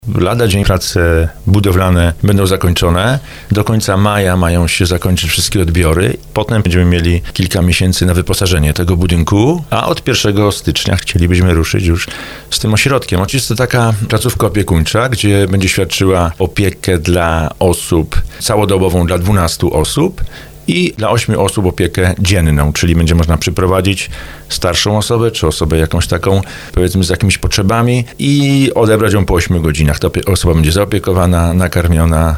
O tym jak przebiegają prace mówił w audycji Słowo za Słowo burmistrz Żabna Tomasz Kijowski.